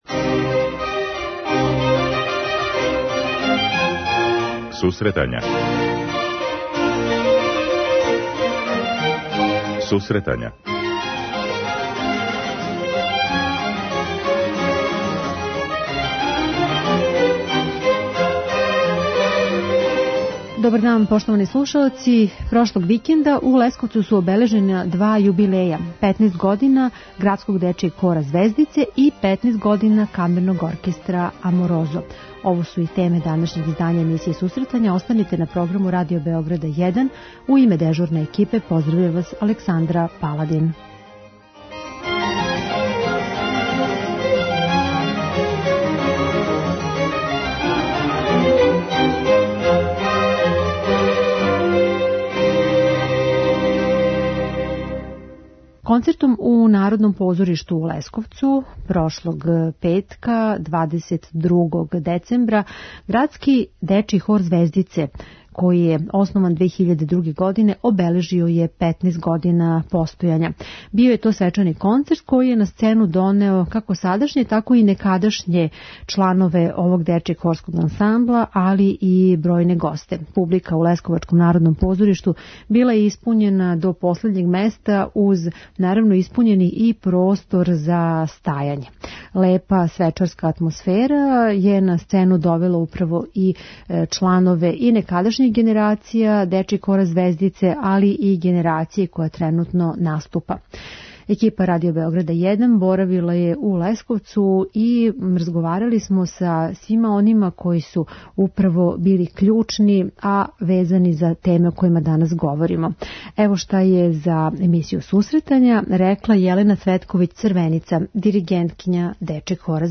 Прошлог викенда обележена су у Лесковцу два јубилеја: 15. рођендан Градског дечјег хора 'Звездице' и Камерног оркестра 'Аморосо'. Данас у емисији преносимо део свечане атмосфере са ових концерата и интересантне разговоре са уметницима.